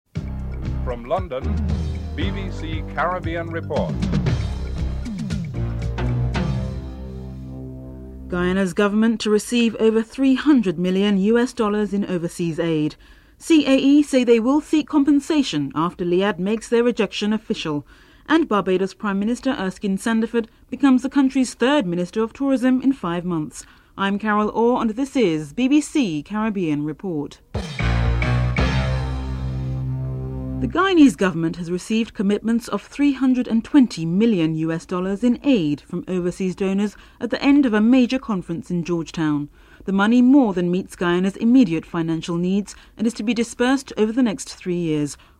Paul Robertson, the Jamaican Foreign Affairs minister is interviewed about the matter(13:05-14:27)
Theme (14:49-15:02)